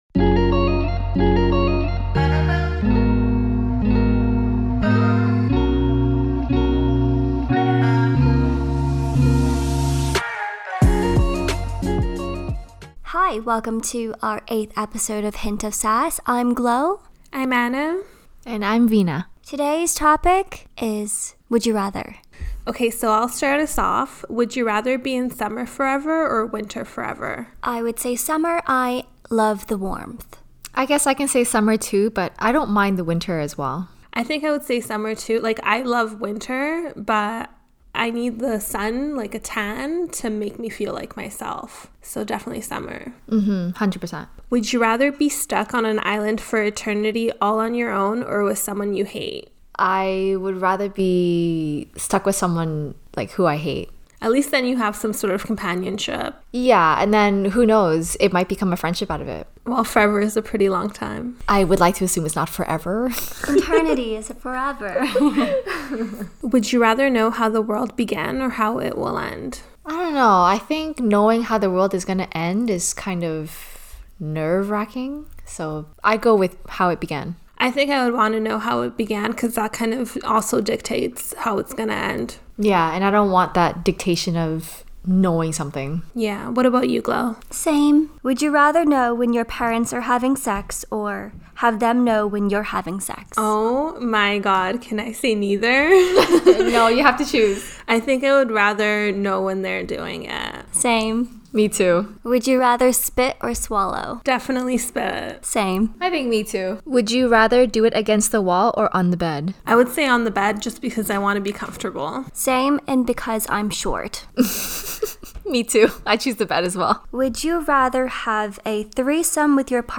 Play Rate Listened List Bookmark Get this podcast via API From The Podcast A trio of ladies discussing everyday topics with a hint of sass.